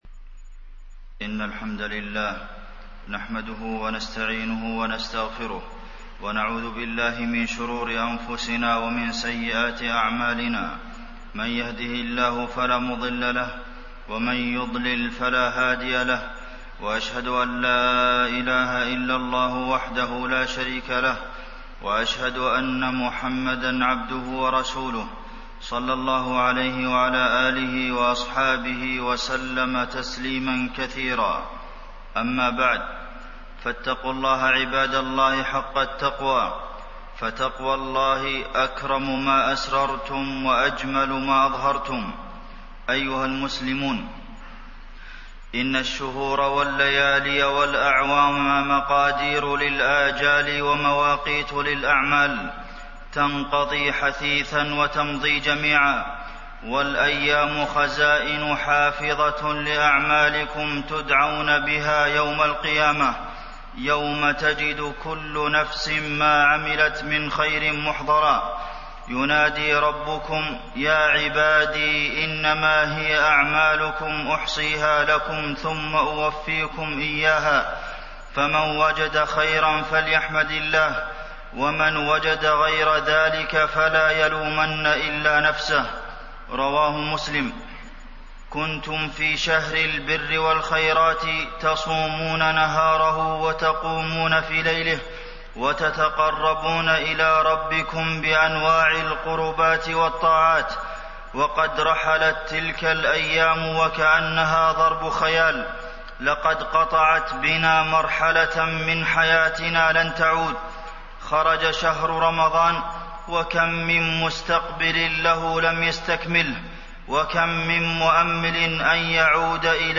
تاريخ النشر ١ شوال ١٤٣١ هـ المكان: المسجد النبوي الشيخ: فضيلة الشيخ د. عبدالمحسن بن محمد القاسم فضيلة الشيخ د. عبدالمحسن بن محمد القاسم ماذا بعد رمضان